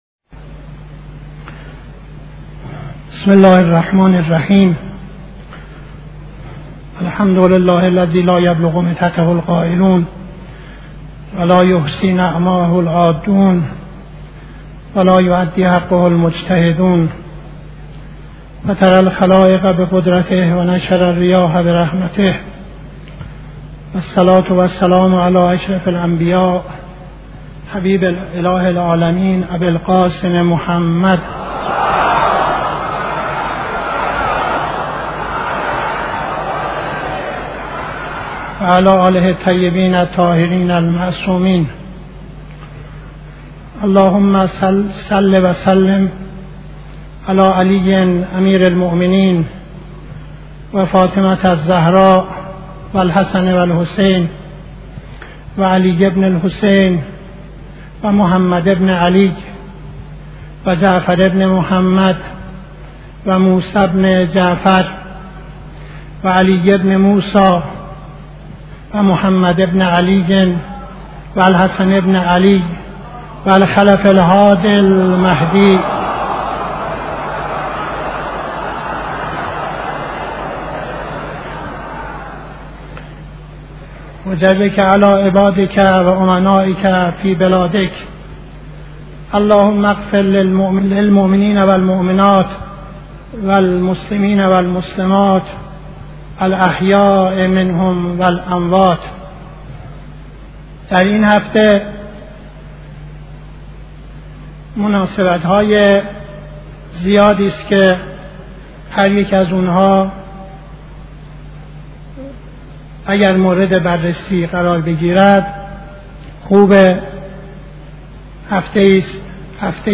خطبه دوم نماز جمعه 18-02-71